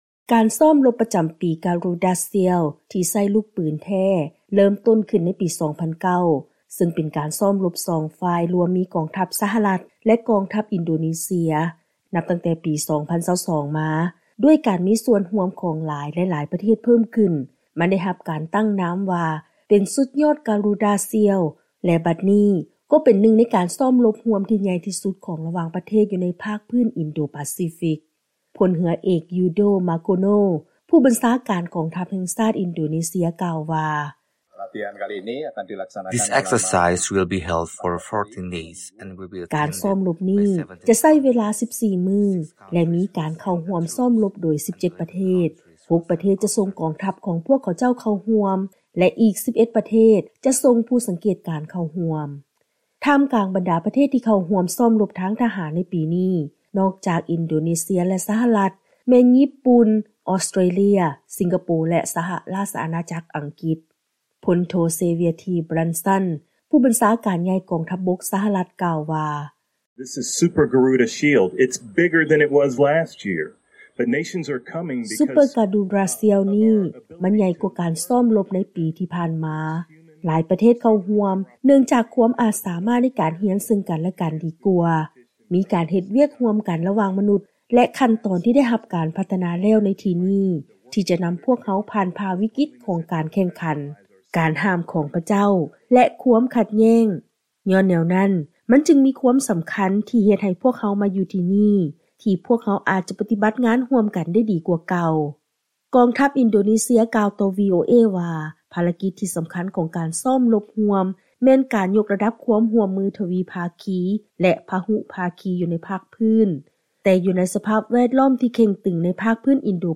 ເຊີນຟັງລາຍງານກ່ຽວກັບ ການຈັດການຊ້ອມລົບຮ່ວມກັນລະຫວ່າງ ກອງທັບສະຫະລັດ ແລະ ກອງທັບອິນໂດເນເຊຍ ຢູ່ໃນພາກພື້ນ ອິນໂດ-ປາຊີຟິກ